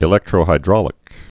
(ĭ-lĕktrō-hī-drôlĭk)